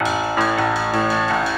keys_17.wav